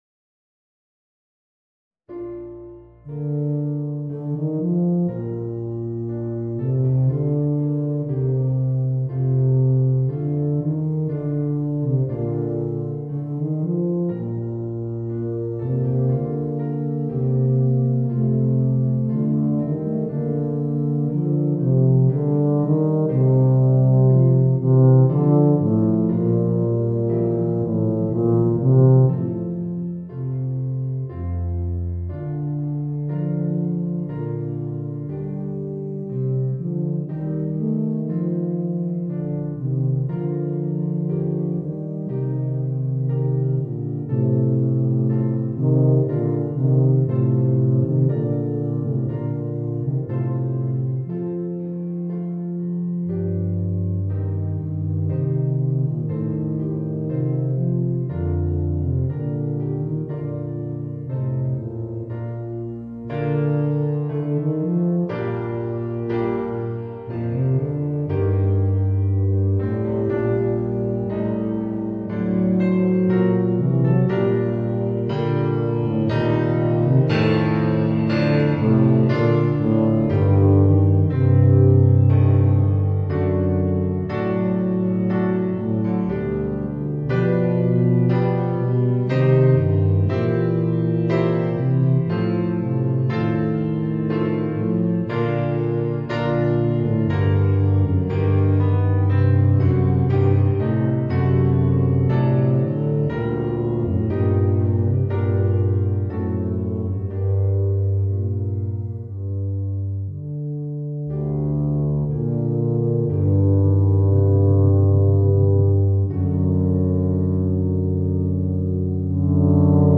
Voicing: 2 Eb Bass and Piano